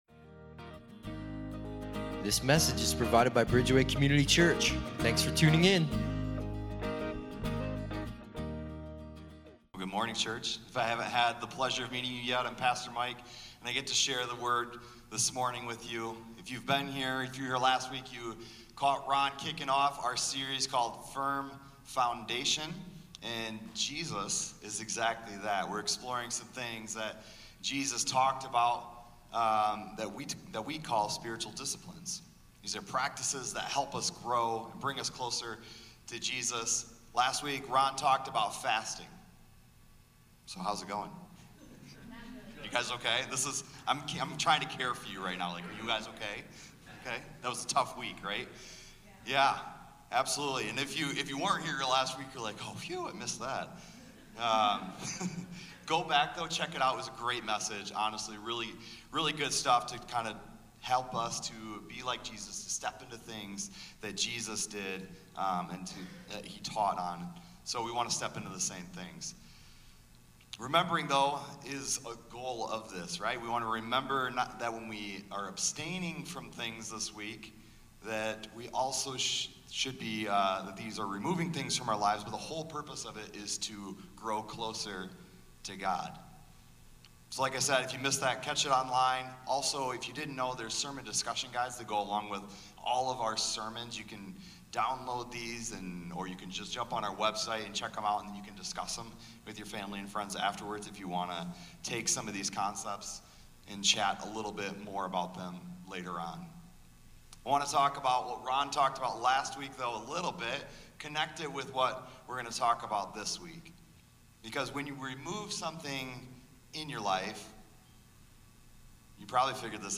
Download Sermon Discussion Guide